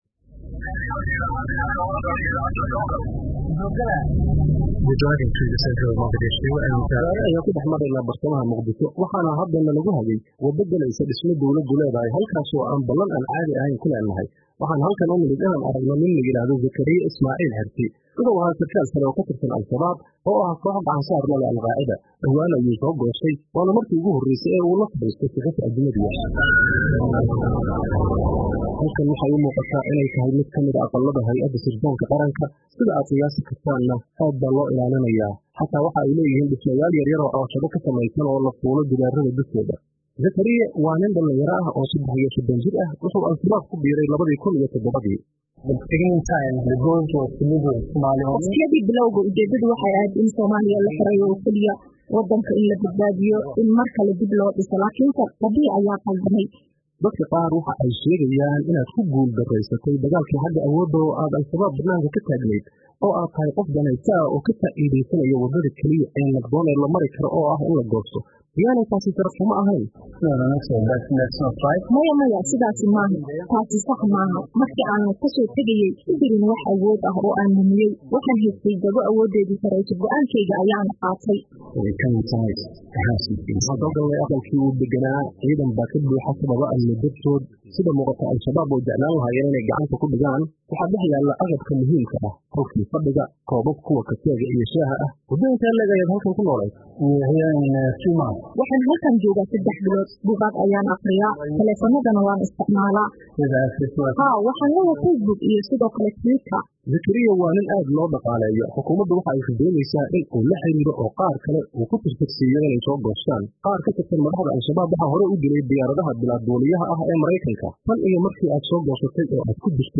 Wareysi uu BBC-da siiyay